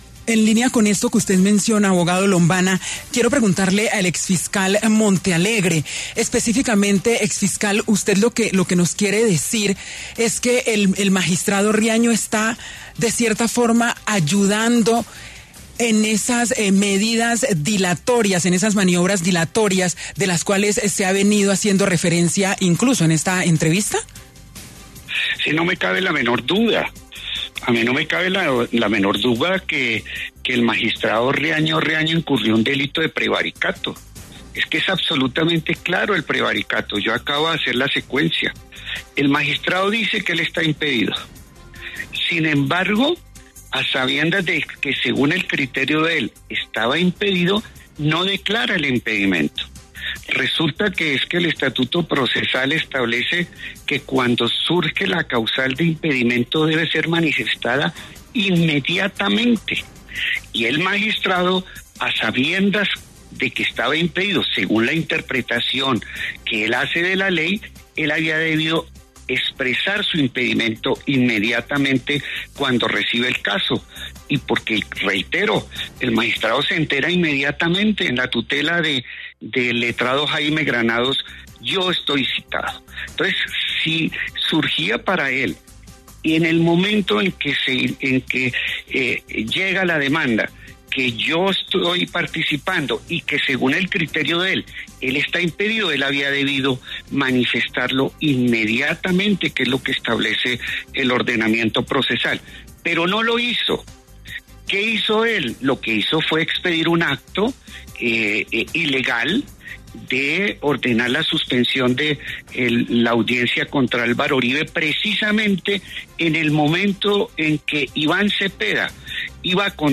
El exfiscal Eduardo Montealegre aseguró, en entrevista con La W, que el expresidente y exsenador Álvaro Uribe es un “criminal de guerra” que debe ser condenado por la justicia colombiana.